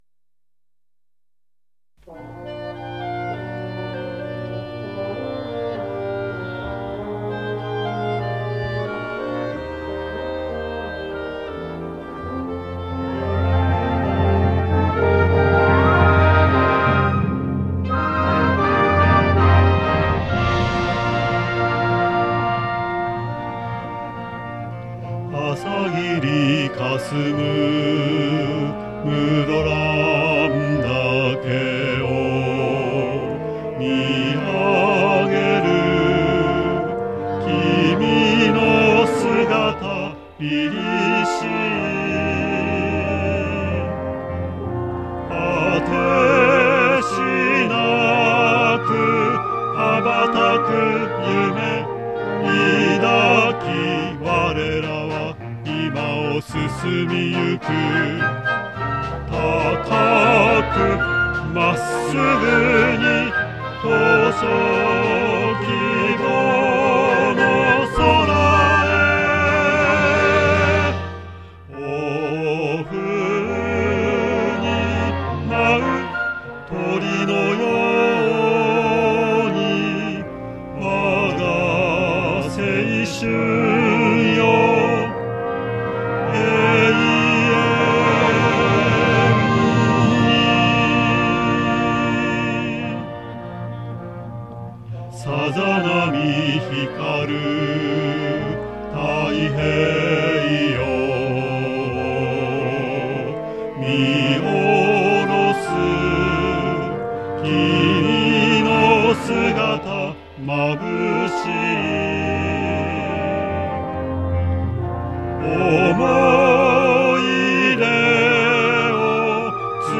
校歌　　作詞：みやた　としひで／作曲：土田　英介